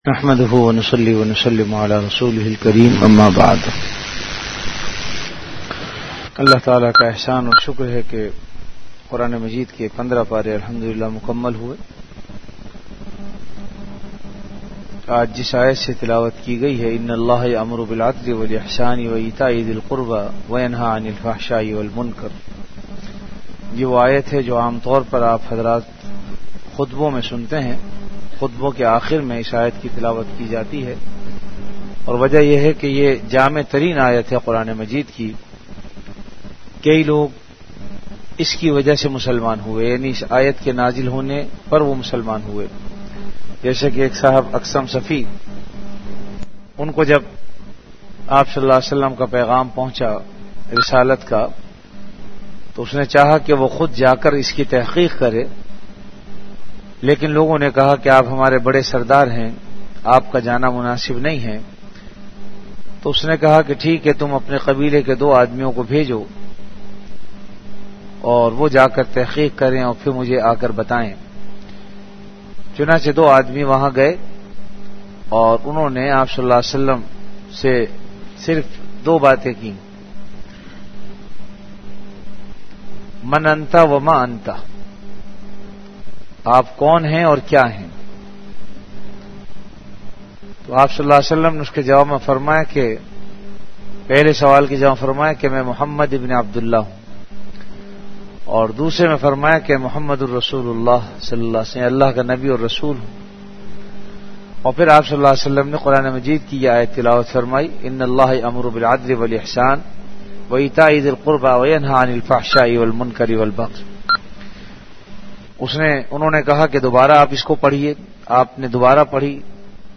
Ramadan - Taraweeh Bayan · Jamia Masjid Bait-ul-Mukkaram, Karachi